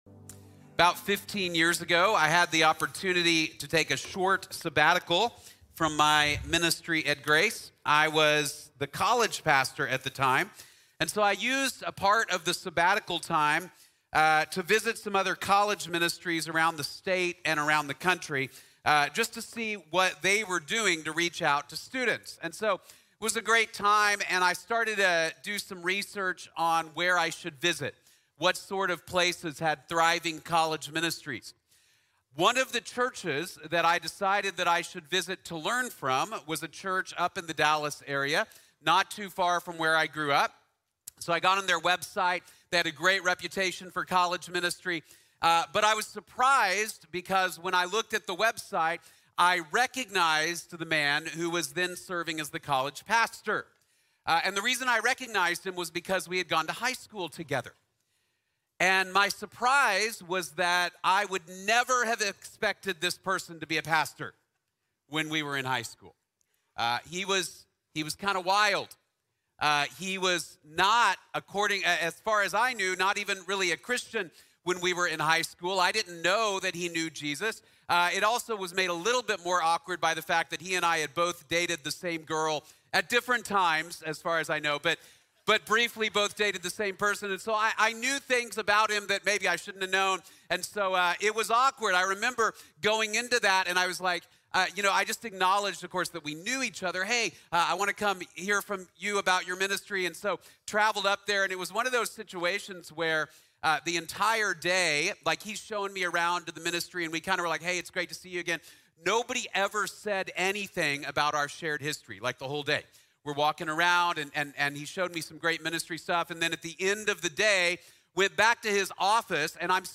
Lost and Found | Sermon | Grace Bible Church